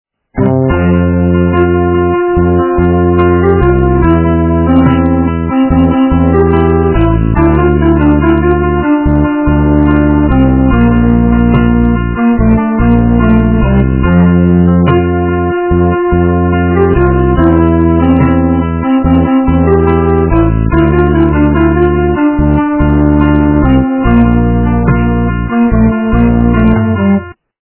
- русская эстрада
полифоническую мелодию